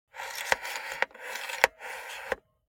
دانلود آهنگ رادیو 19 از افکت صوتی اشیاء
جلوه های صوتی